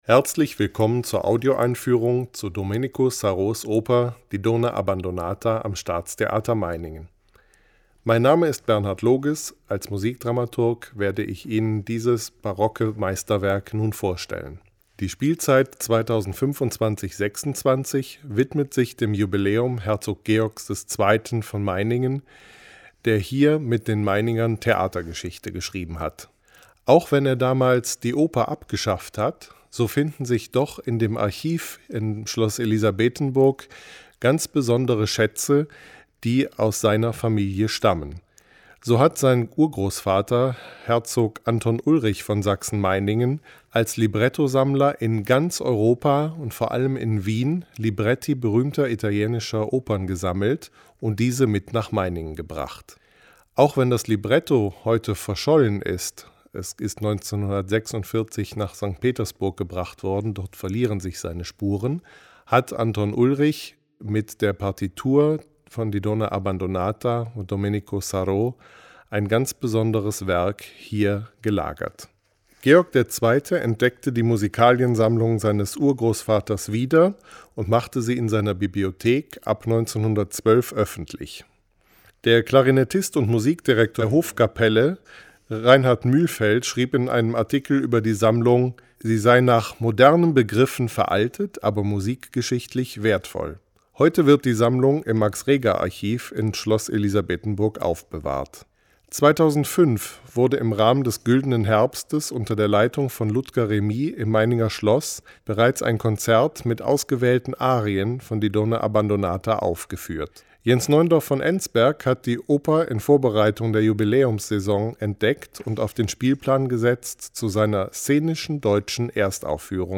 Audioeinführung zum Stück